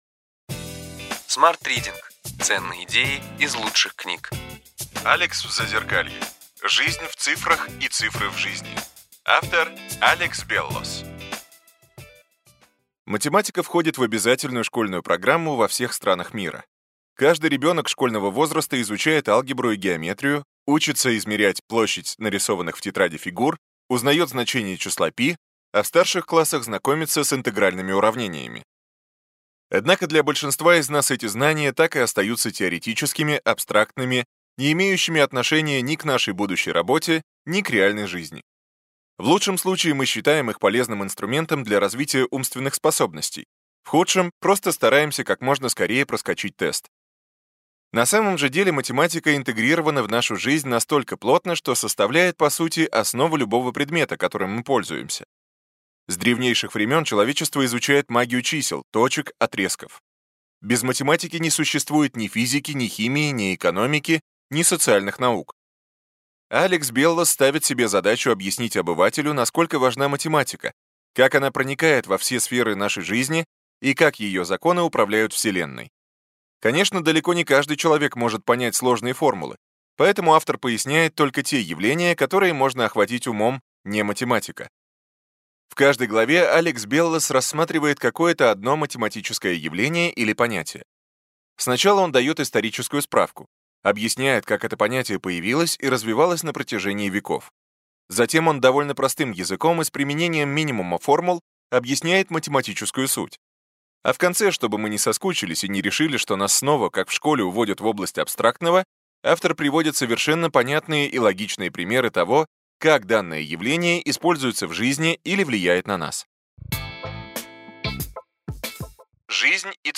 Аудиокнига Ключевые идеи книги: Алекс в Зазеркалье. Жизнь в цифрах и цифры в жизни.